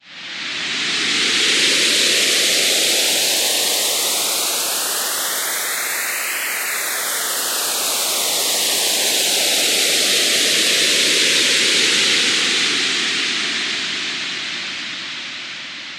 酸性风